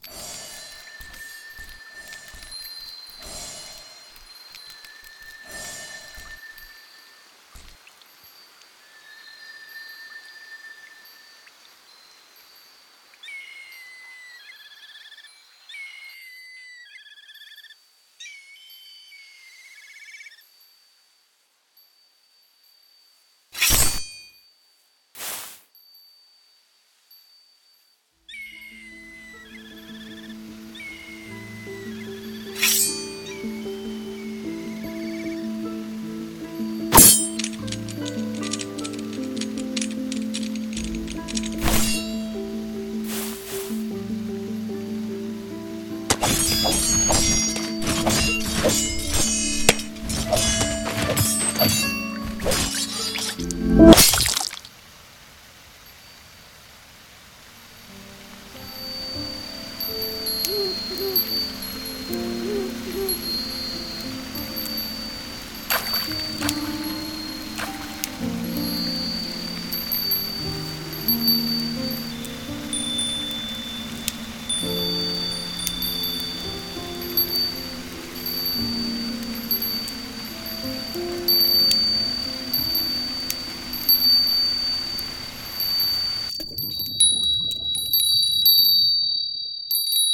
声劇】雨垂れ喪